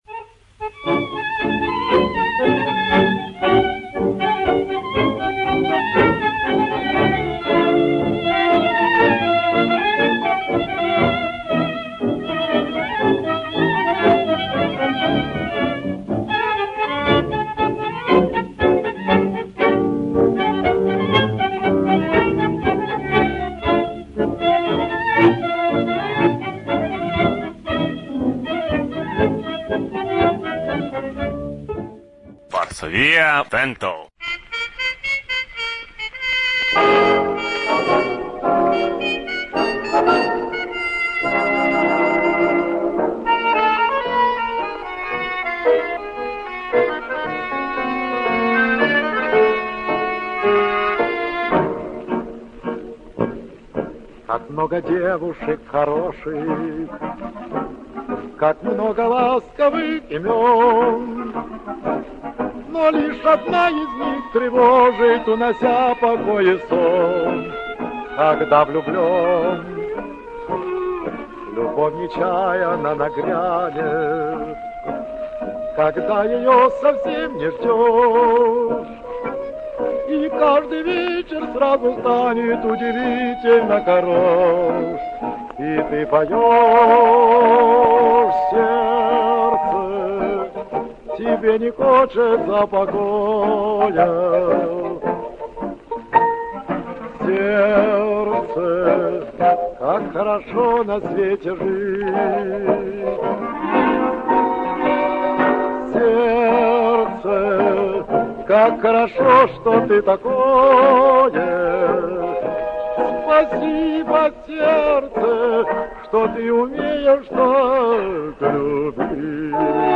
Sonbildo de la varsovia Merkreda Rondo
Sonbildo de la fortaleza grupo de lernado